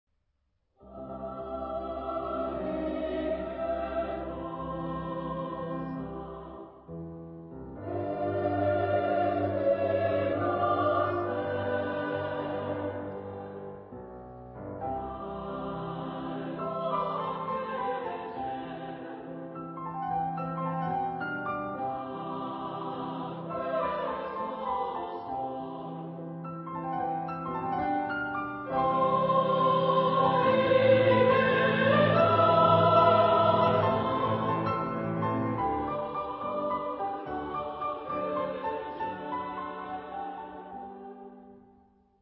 Genre-Style-Forme : Romantique ; Profane ; Lied
Type de choeur : SSA  (3 voix égales de femmes )
Instruments : Piano (1)
Tonalité : do majeur